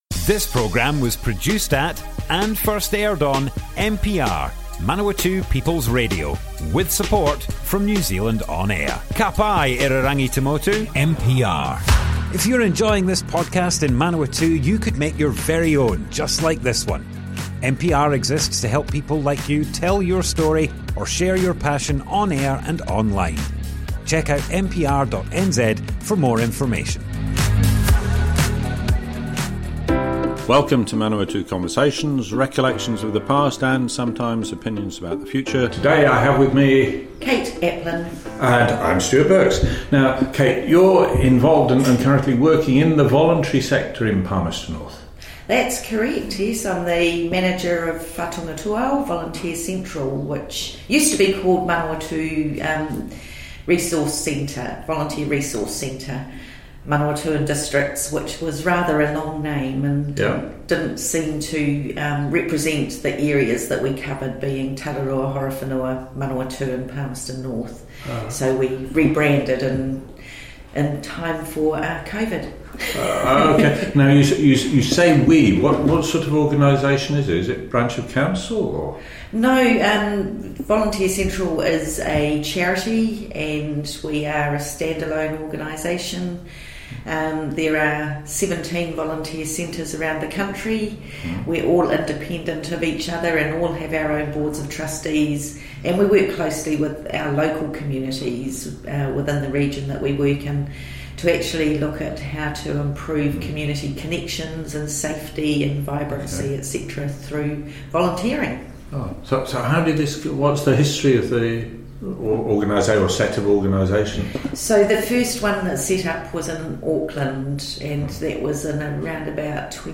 Manawatu Conversations More Info → Description Broadcast on Manawatu People's Radio, 24th December 2024.
oral history